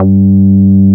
P MOOG G3P.wav